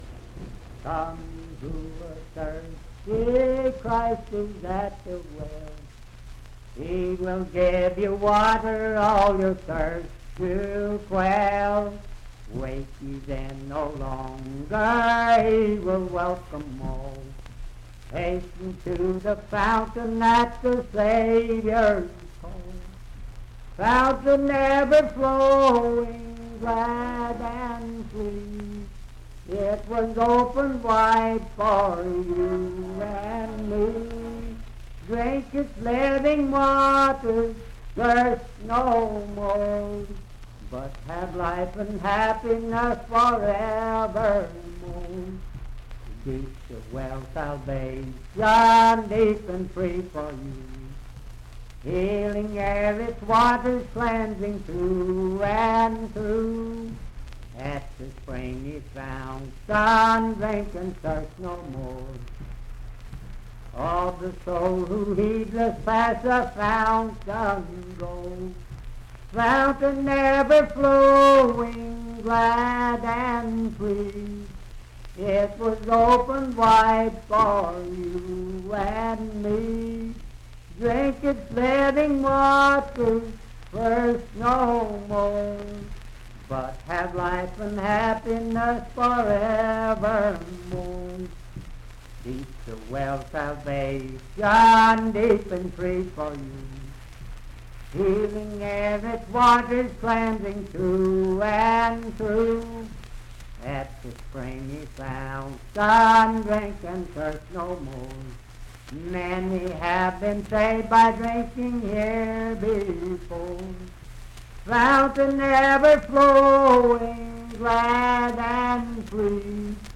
Unaccompanied vocal music and folktales
Hymns and Spiritual Music
Voice (sung)
Wood County (W. Va.), Parkersburg (W. Va.)